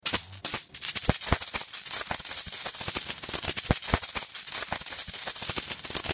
Звуки копилки
Потряхивание копилки:
potrjahivanie-kopilki.mp3